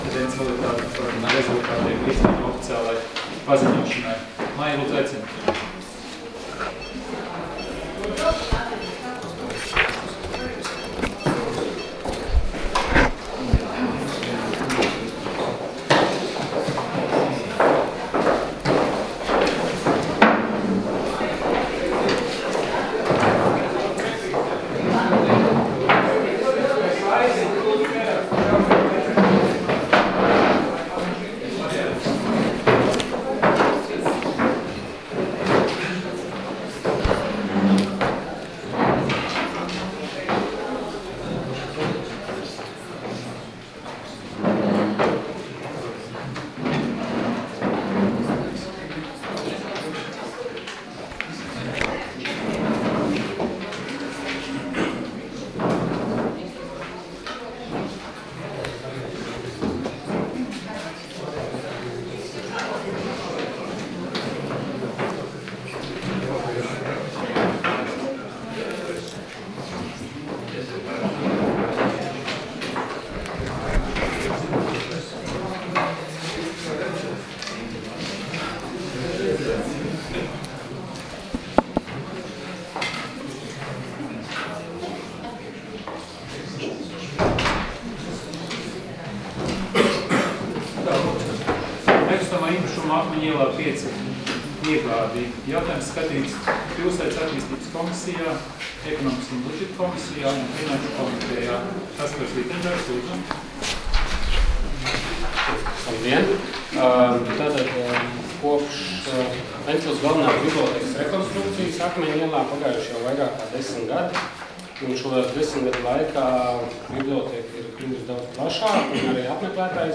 Domes sēdes 30.10.2015. audioieraksts